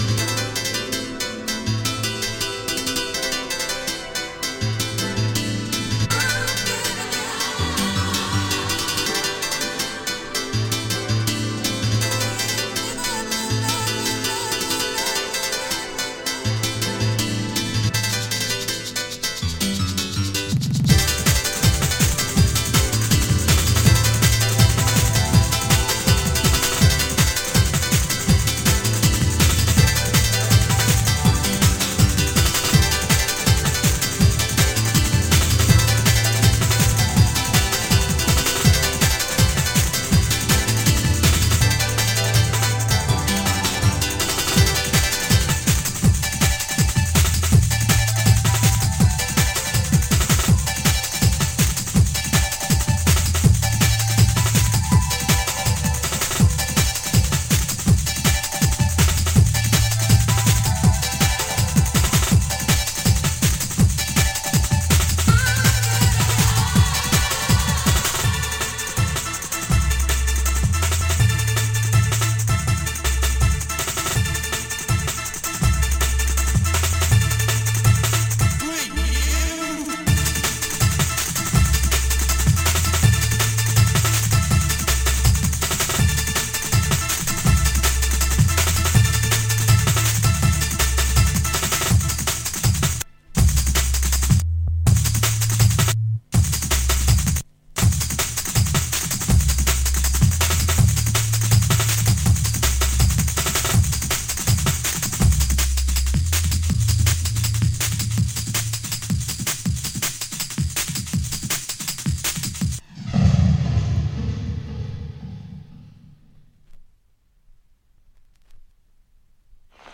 Jungle-techno essencial, a olhar novas possibilidades.